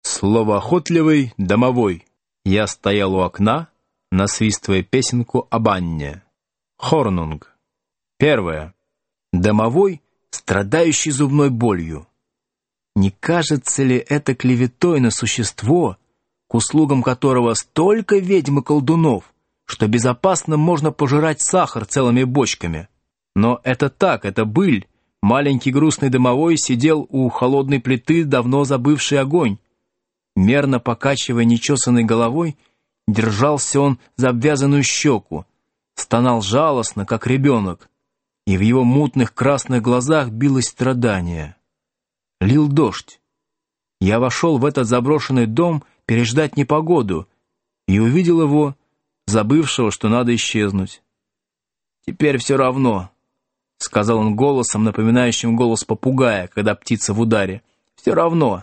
Aудиокнига Рассказы